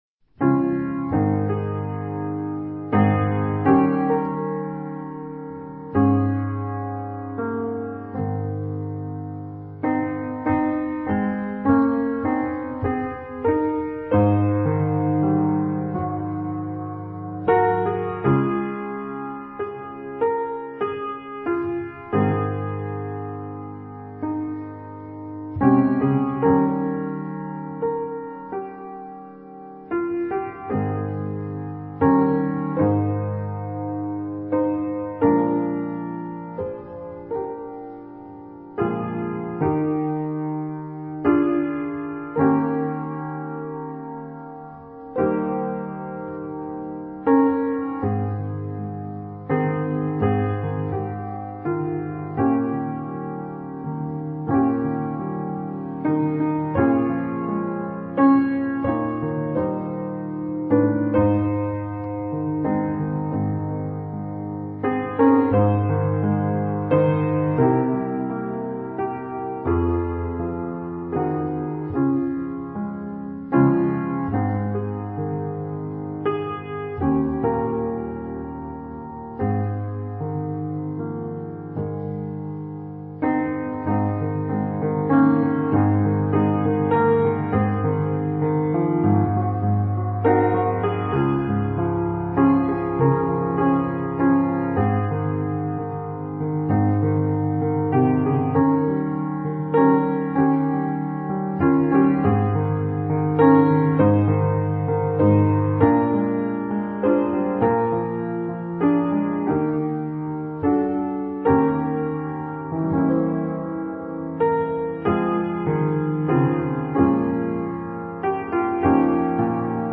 Mainly Piano
Easy Listening   2 146.6kb